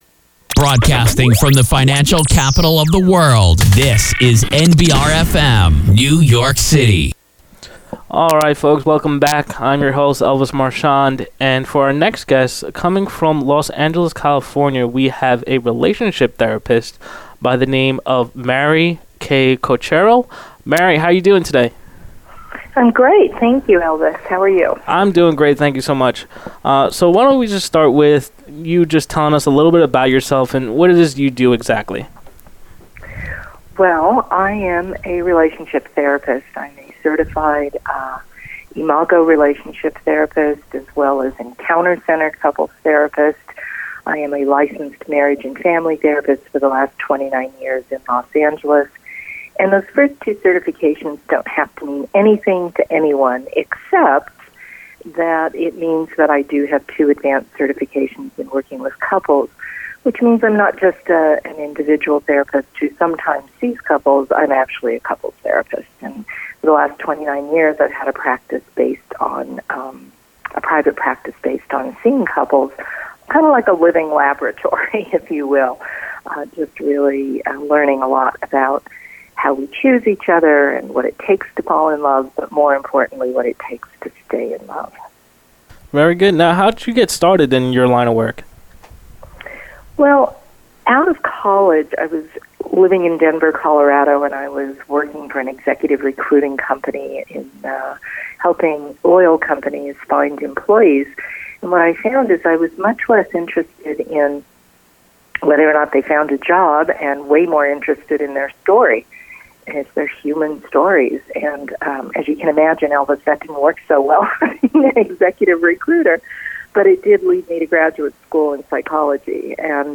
All Business Media Interview